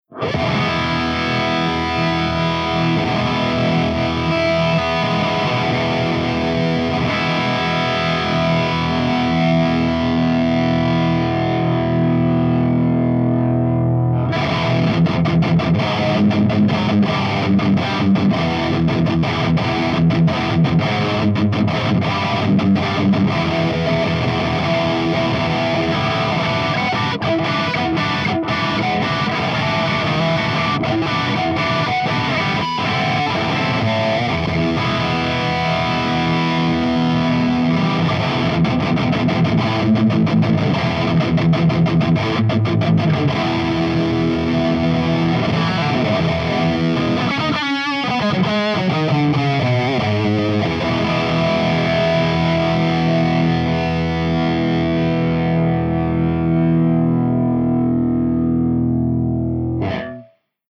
166_EVH5150_CH3HIGHGAIN_V30_HB